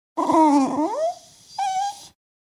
Dog Whimpering In Disappoint Sound Effect Download: Instant Soundboard Button
Dog Sounds2,554 views